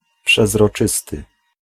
Ääntäminen
IPA : /trænsˈpærənt/